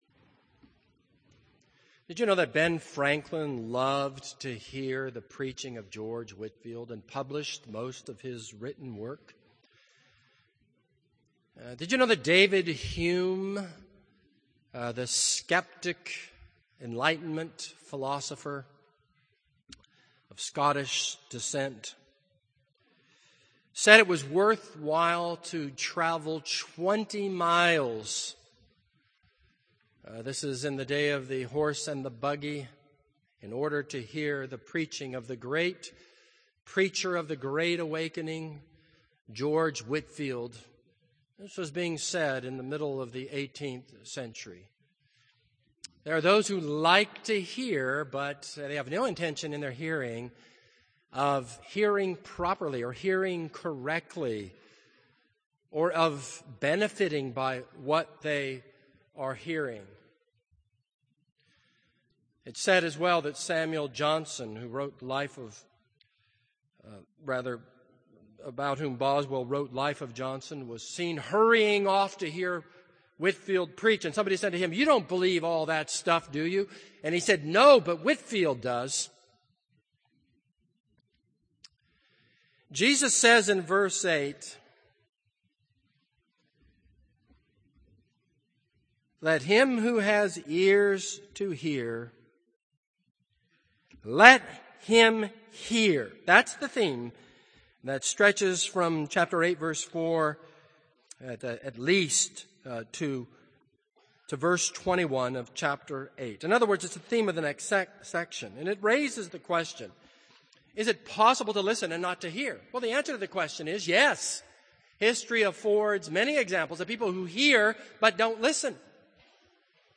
This is a sermon on Luke 8:4-8 and Luke 8:11-15.